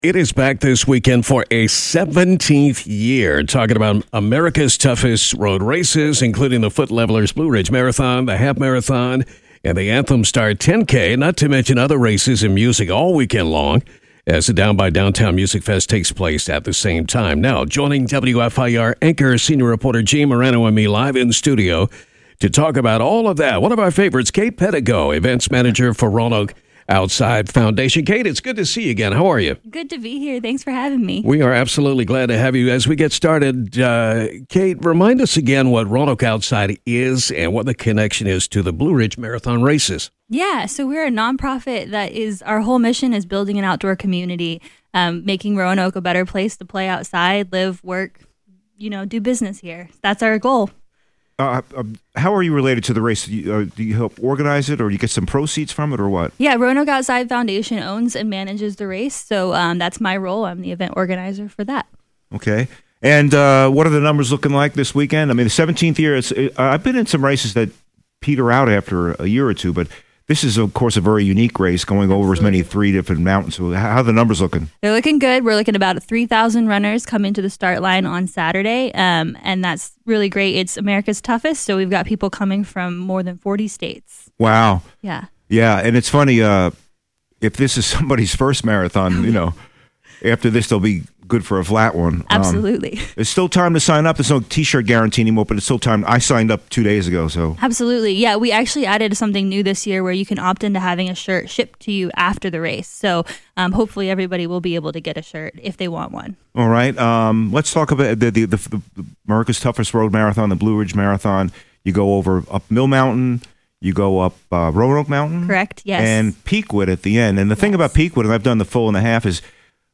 Hear the complete conversation below or watch it on our Facebook page.